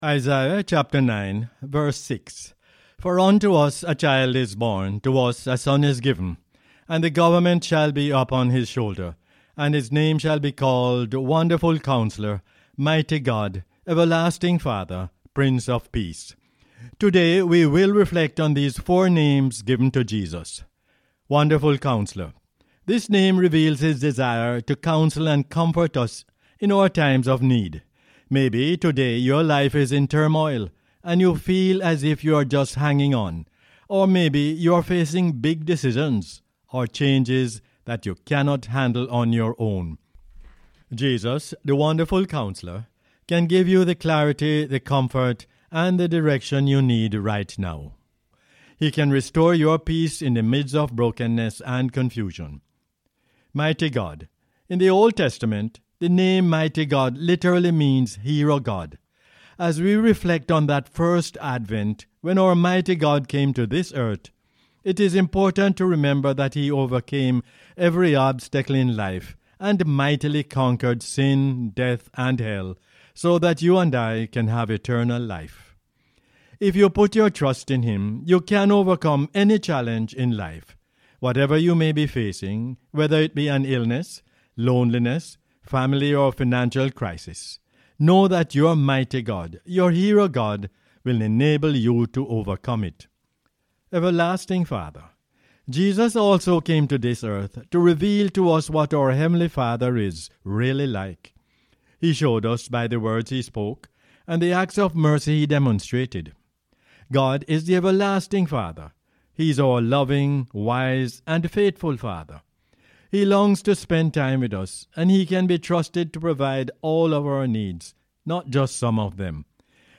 Isaiah 9:6 is the "Word For Jamaica" as aired on the radio on 9 December 2022.